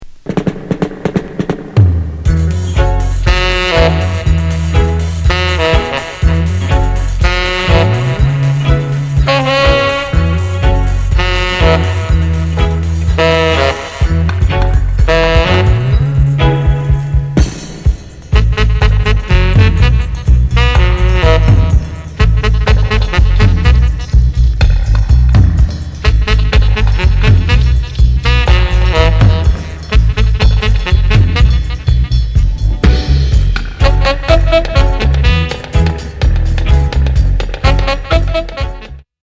フューチャーした大人気インスト・バージョン！！